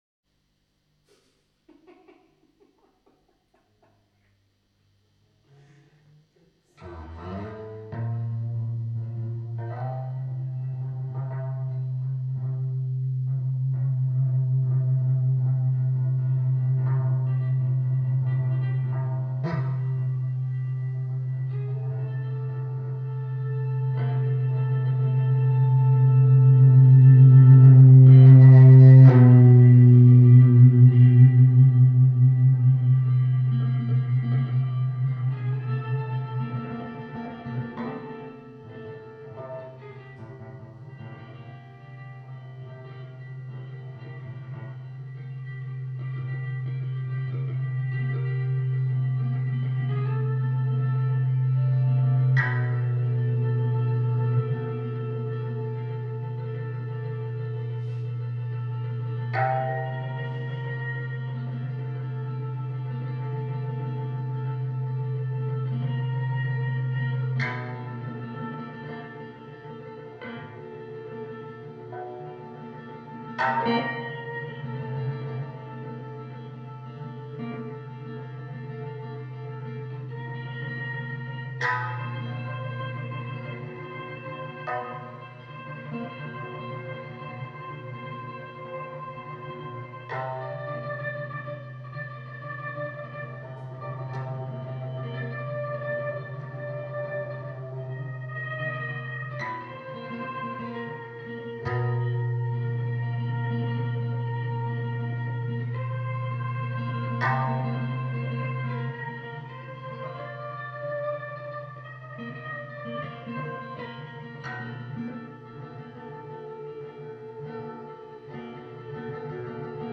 stereo
Live recorded
drums